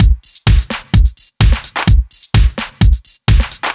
Index of /musicradar/french-house-chillout-samples/128bpm/Beats
FHC_BeatA_128-01.wav